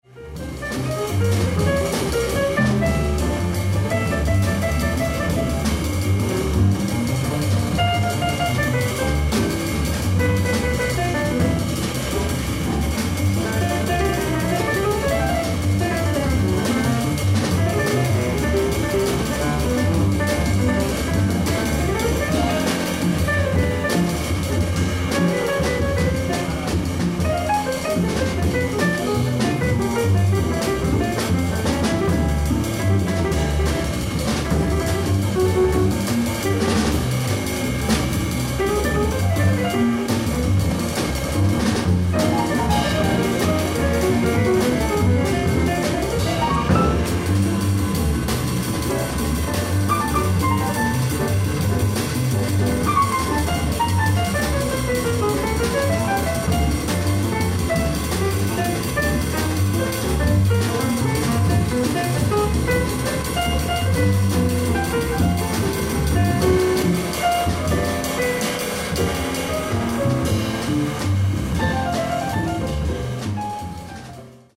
ライブ・アット・ダコタ・ジャズクラブ、ミネアポリス、ミネソタ 12/04/2017
ミネアポリスのジャズクラブ「ダコタ・ジャズクラブ」に出演したライブを高 音質オーディエンス音源より収録してます。
※試聴用に実際より音質を落としています。